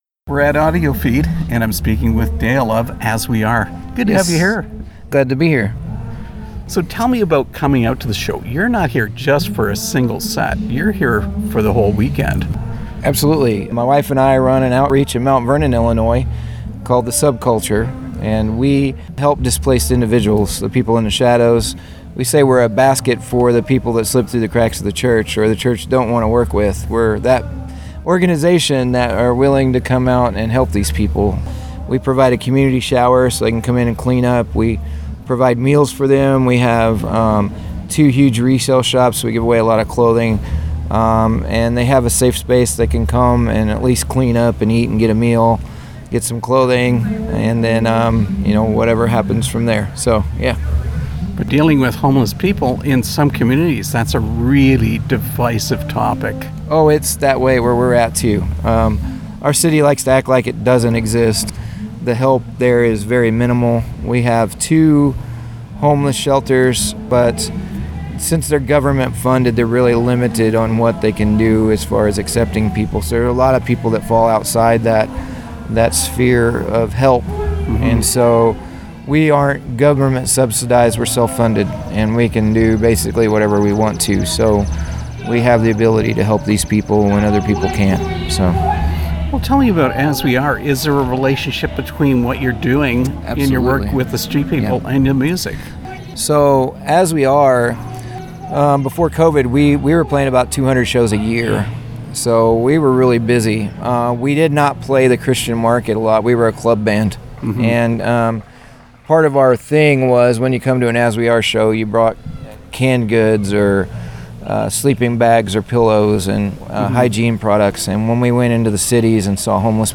Interview with As We Are
as-we-are-interview.mp3